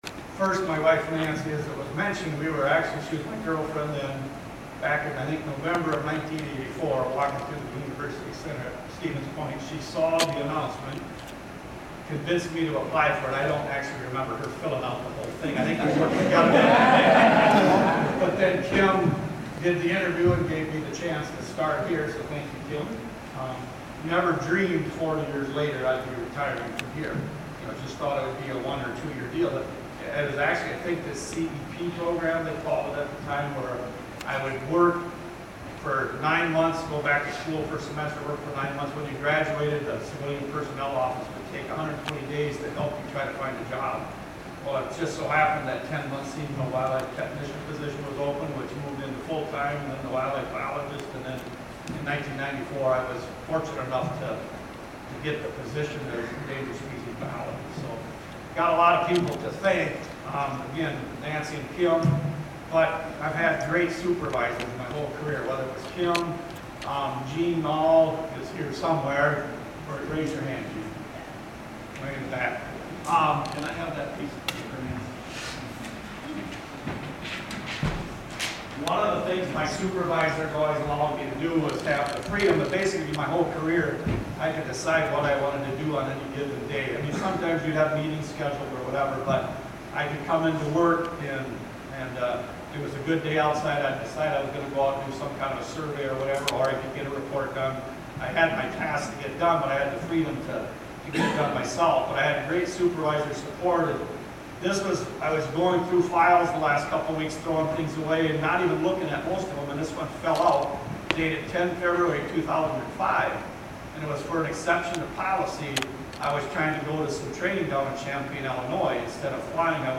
40-year Army employee discusses career during retirement ceremony, Part 1